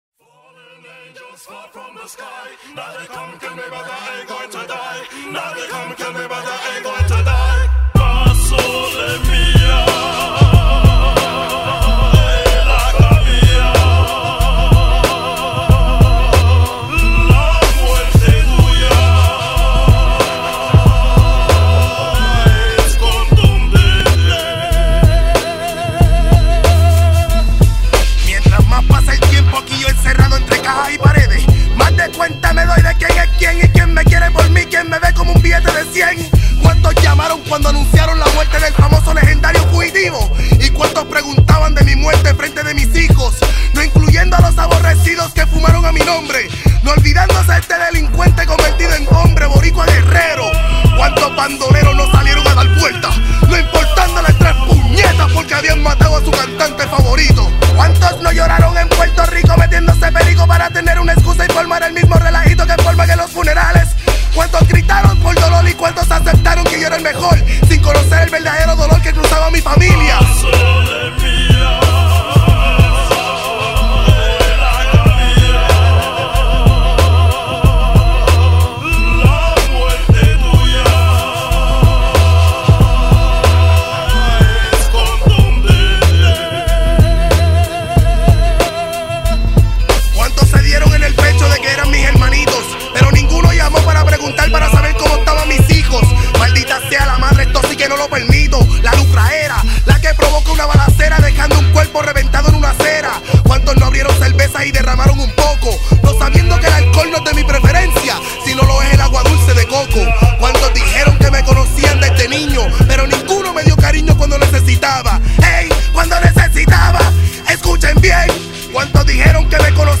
Categoría: Hip Hop PuertoRico Etiquetas: , , ,